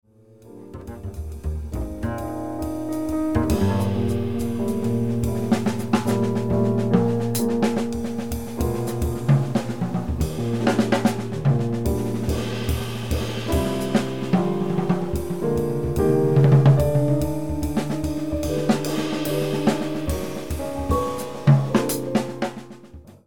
drum solo 1 chorus (piano comping)
sticks throughout on the recording